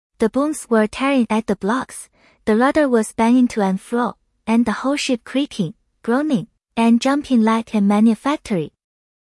xiaoxiao(edgetts).mp3